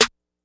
Metro Snare 8 .wav